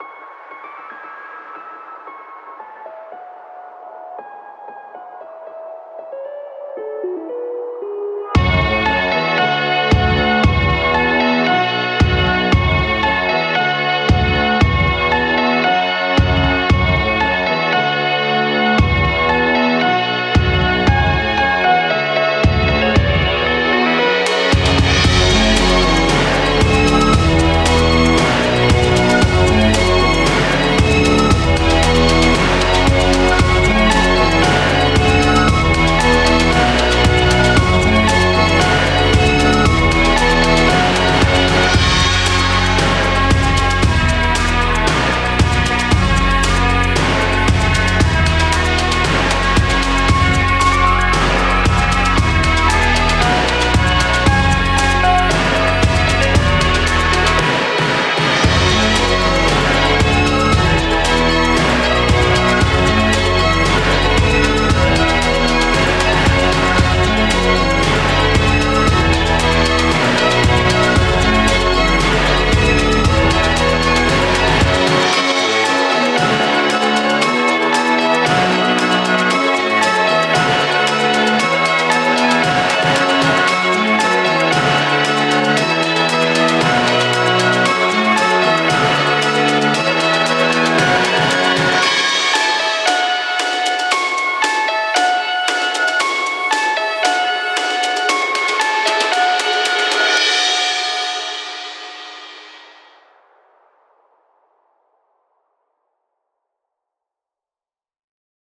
NOT the typical stuff i usually make (orchestral type track lol). pretty much 'throwaway', please excuse the horrendous mix lol.
orchestralsong_10.wav